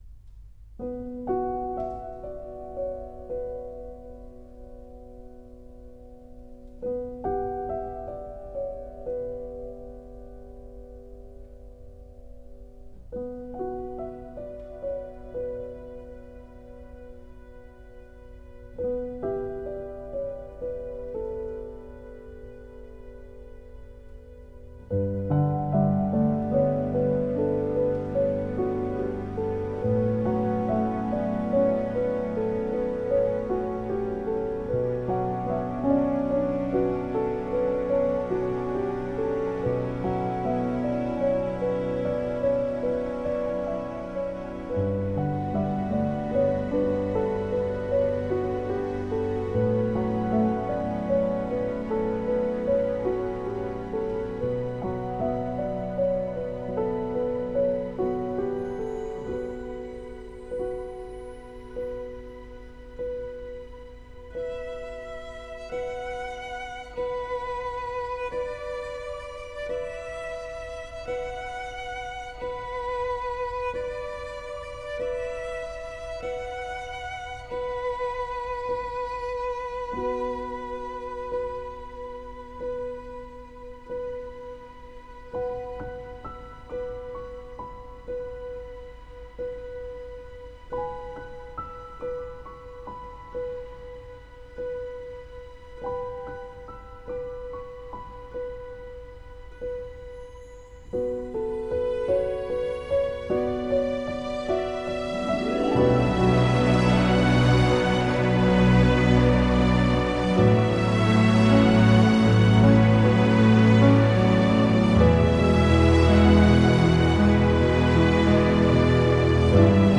موسیقی بیکلام
امبینت